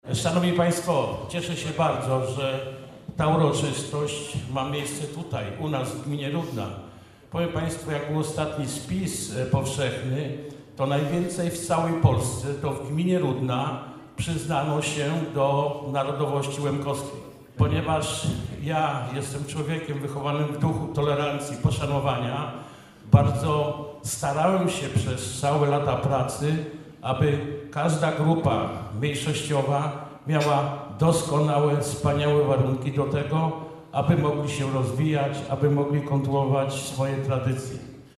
В осередку культуры в Рудній, в суботу, 28. квітня/апріля 2018 р., зышли ся давны і теперішні члены ґрупы, жебы приняти желаня і ґратуляциі, поспоминати і погостити ся з тыма, якы за остатніх 10 років підпорювали і помагали ґрупі успішні ся розвивати.
Владислав Біґус, війт ґміны, надзвычай сердечні розповідал про Лемків і стараня самоурядовых власти, жебы помочы ініциятивам, якы мают за ціль сохранити і розвивати лемківску культуру, лемківскій фольклор. Зазначыл, што добрі розуміє тугу за втраченом вітчызном, бо, як вельо жытелів ґміны, єст переселенцьом.
wojt-gminy-Rudna-w--adys--aw-Bigus.mp3